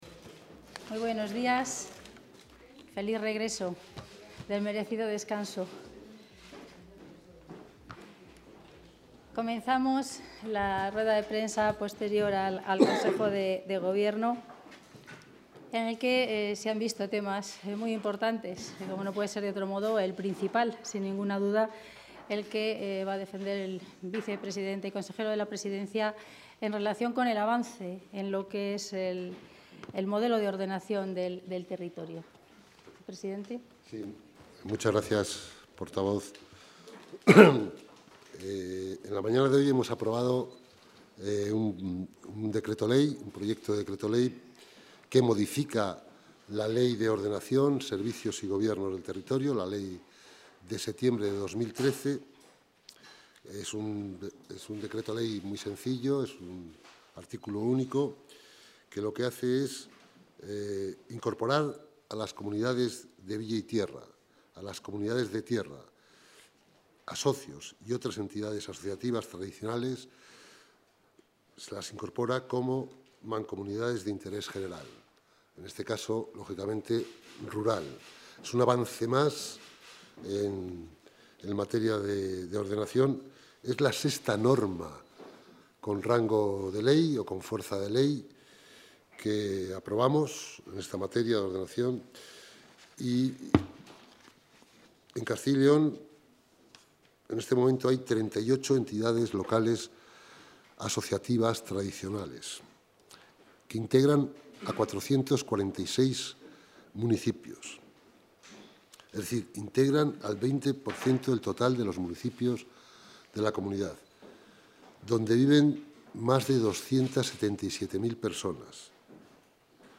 Rueda de prensa tras el Consejo de Gobierno.